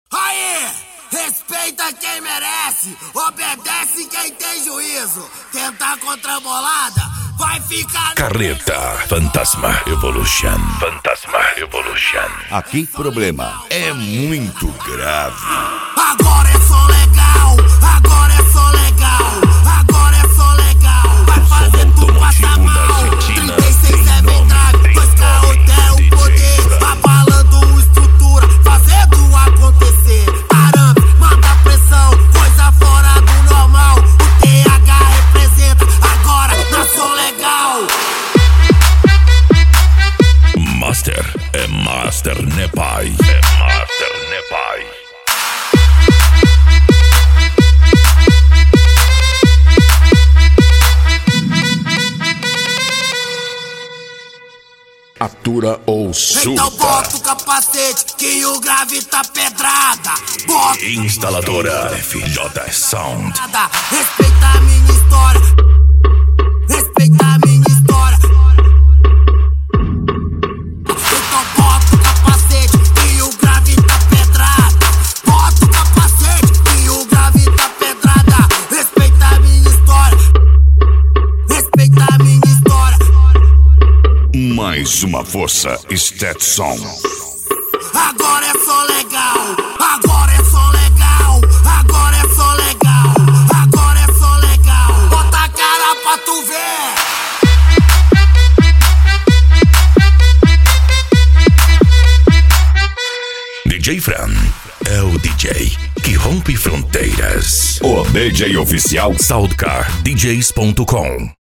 Bass
Deep House
Eletronica
Mega Funk